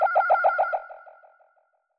capricorn laeser.wav